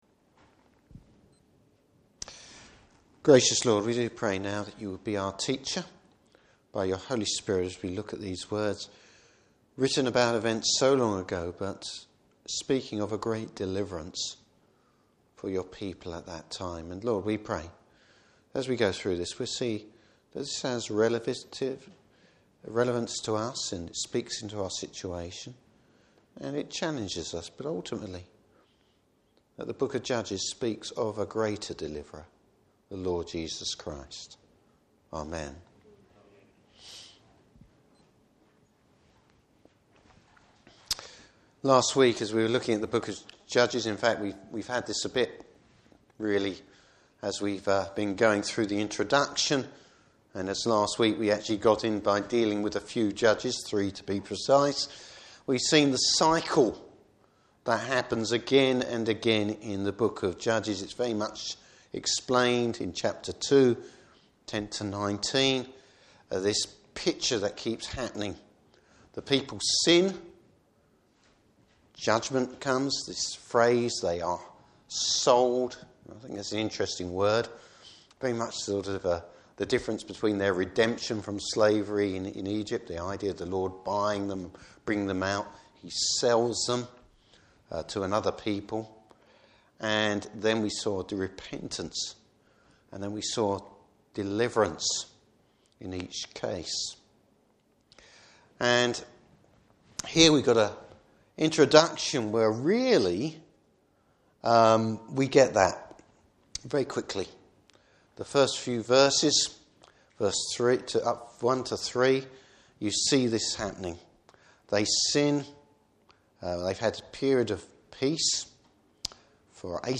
Service Type: Evening Service Bible Text: Judges 4.